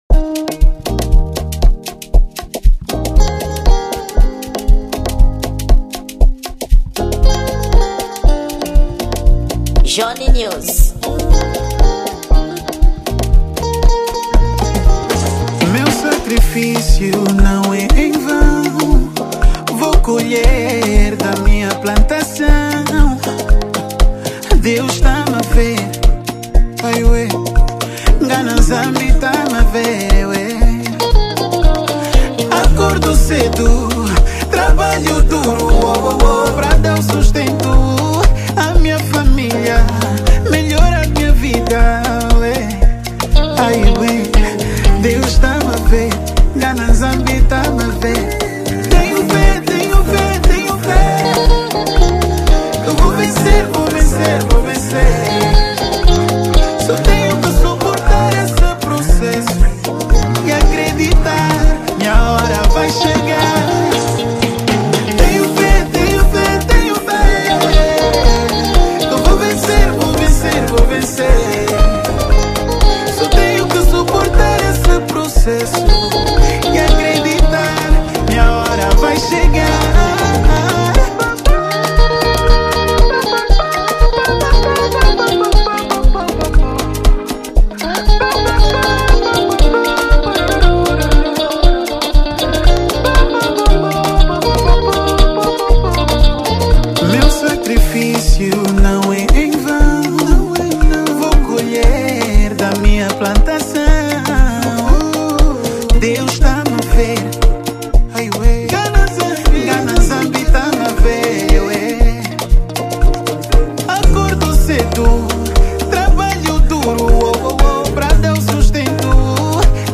Gênero: Amapiano